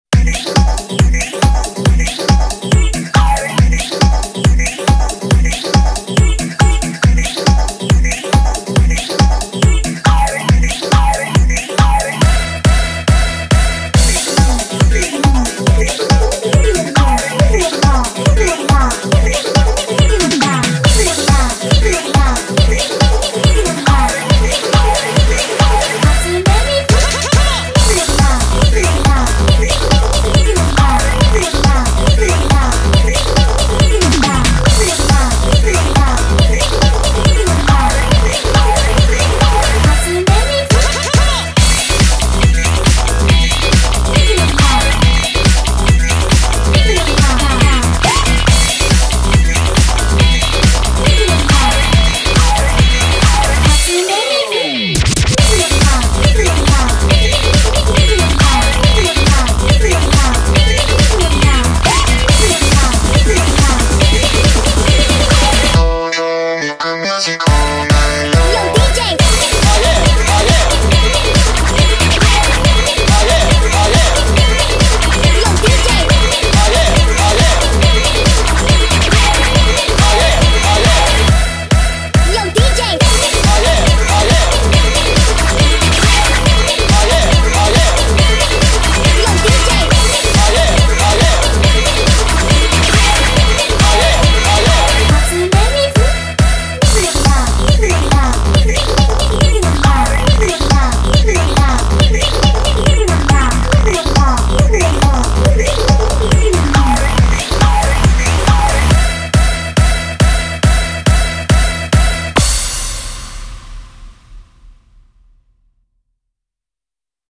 BPM139
MP3 QualityMusic Cut